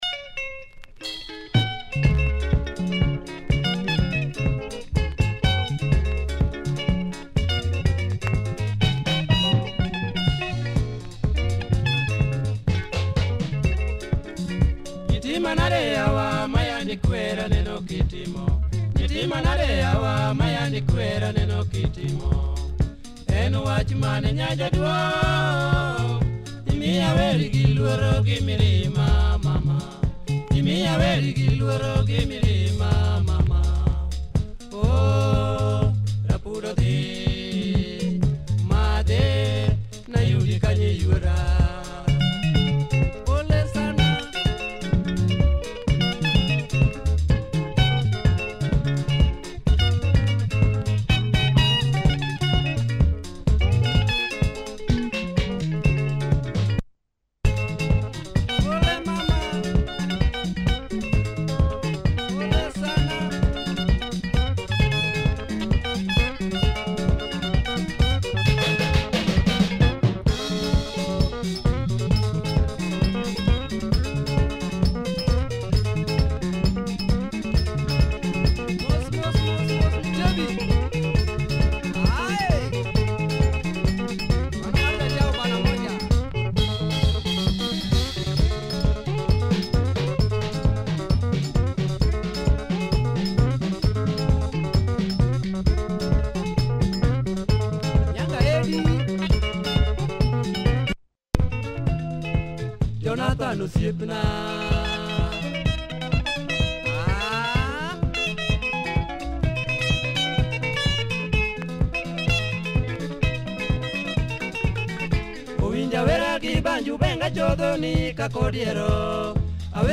Great luo benga with a good tempo.